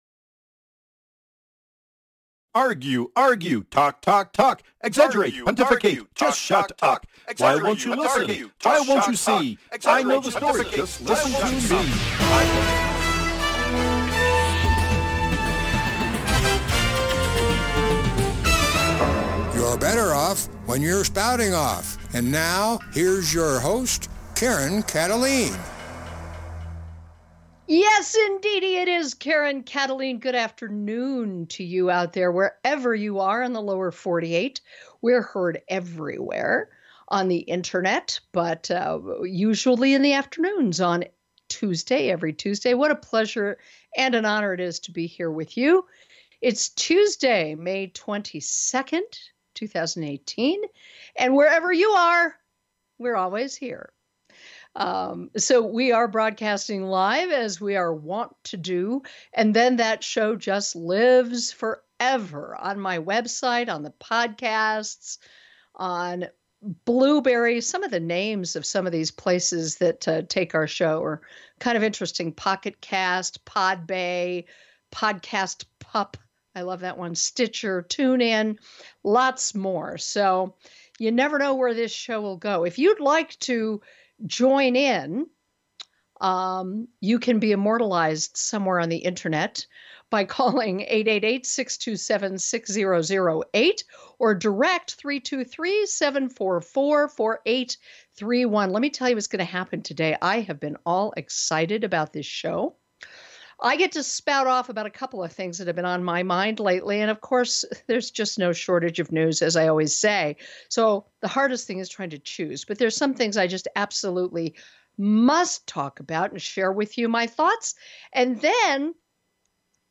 Talk Show Episode, Audio Podcast, Spouting Off and Guest